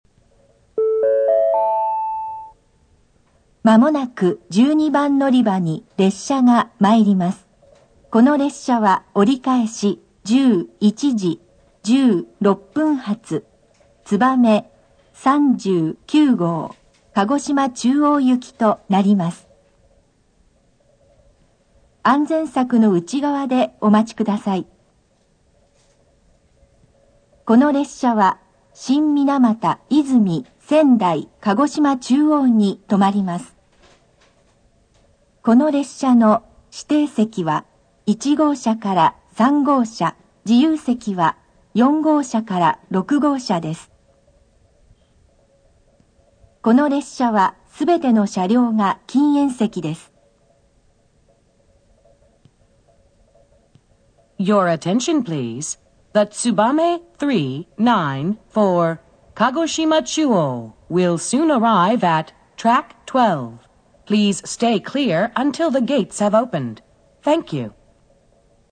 UNI-PEX(小型)　新幹線
放送・メロディー共に九州新幹線全駅で共通です。
接近放送 つばめ39号・鹿児島中央 (335KB/68秒)
全線・全駅、シリウスによる放送で、上りホームに男性・下りホームに女性放送が基本であるが、駅や番線によって多少異なります。
ホーム柱各所にスピーカーが配置され、九州では初登場となったユニペックスのCWS25（通称：ユニペックス小型）を設置。